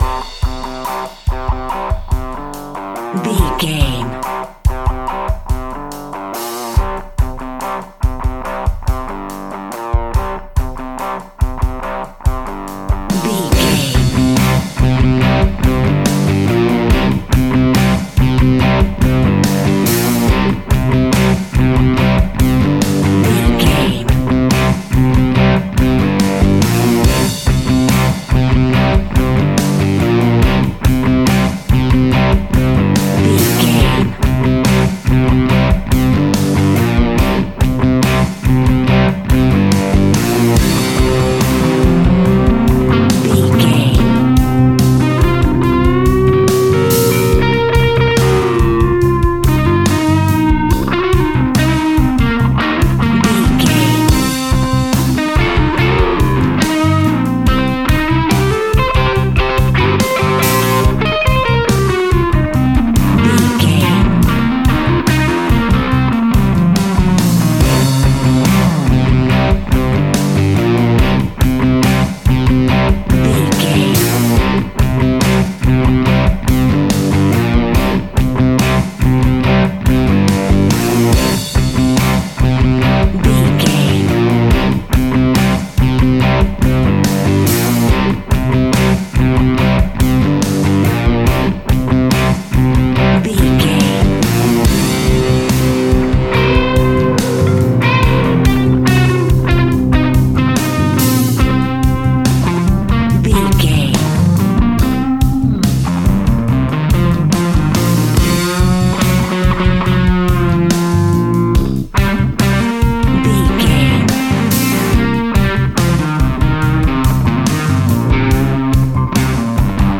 Aeolian/Minor
energetic
driving
aggressive
electric guitar
bass guitar
drums
hard rock
heavy metal
blues rock
distortion
instrumentals
distorted guitars
hammond organ